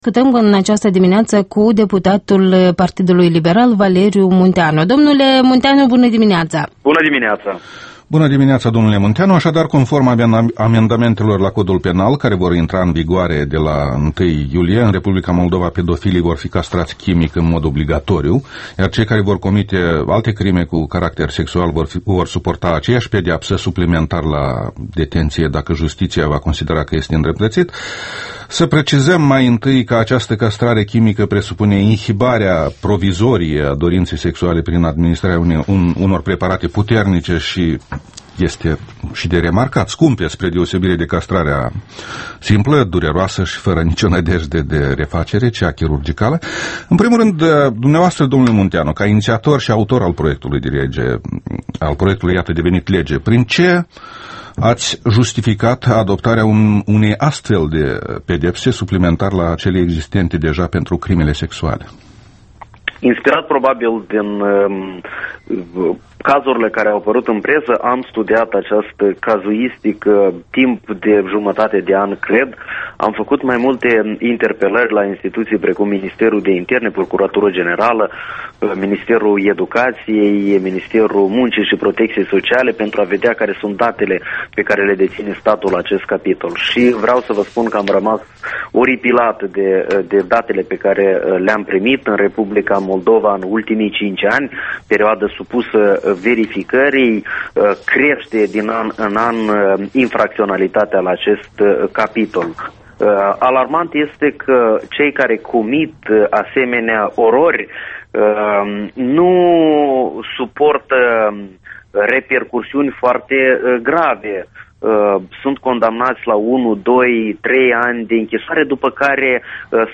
Interviul dimineții la Europa Libera: cu deputatul Valeriu Munteanu despre pedepsirea pedofililor